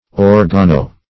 Search Result for " organo-" : The Collaborative International Dictionary of English v.0.48: Organo- \Or"ga*no-\ [See Organ .] A combining form denoting relation to, or connection with, an organ or organs.